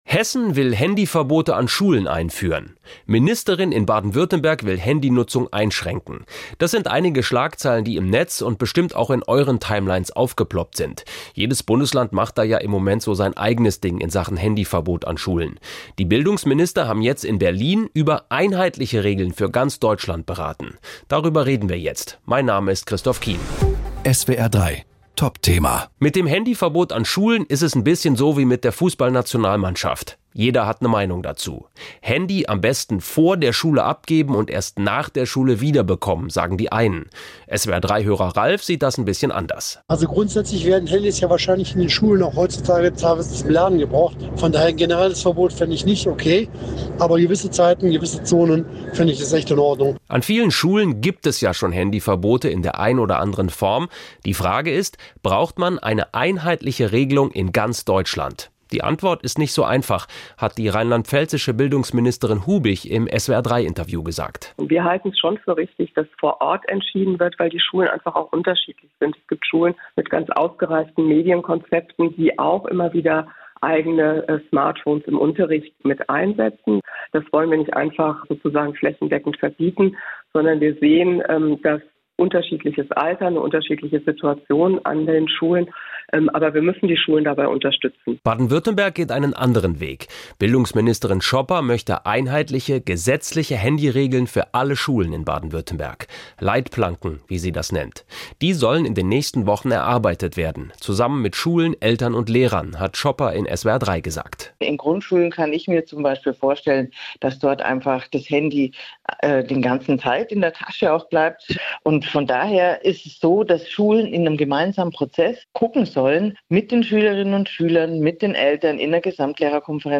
Wir haben exklusiv mit den Bildungsministerinnen von Baden-Württemberg, Schopper, und Rheinland-Pfalz, Stefanie Hubig (SPD) gesprochen, wie die Situation rund ums Handy an Schulen ist und welche Pläne es gibt.